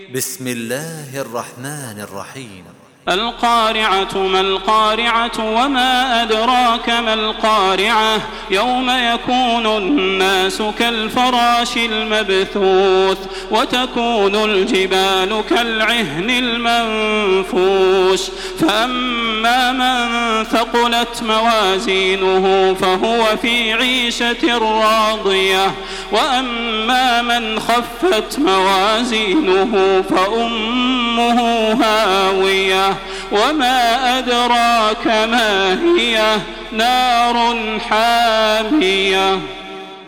دانلود سوره القارعه توسط تراويح الحرم المكي 1427
مرتل